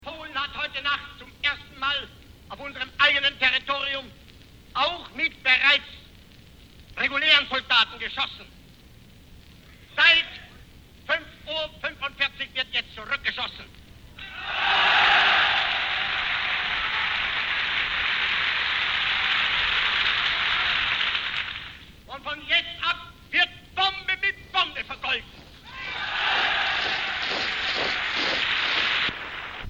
Hier beneden kunt u wat foto's uit die tijd bekijken en als u helemaal beneden op het toonbandje klikt-zet wel even mijn muziek uit!- hoort u Hitler tekeergaan over de brutalitet van de Poolse Regering.
2 Op toonband vastgelegde rede Hitler.mp3 (466 KB)